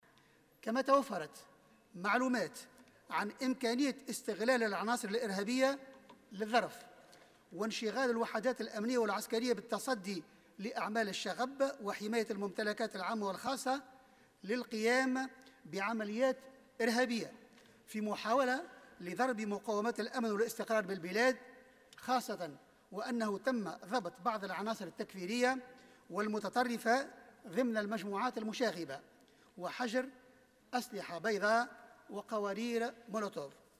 وأضاف خلال جلسة عامة اليوم بالبرلمان أن ذلك يأتي بعد توفر معلومات عن إمكانية استغلال عناصر إرهابية للظرف والقيام بعمليات إرهابية.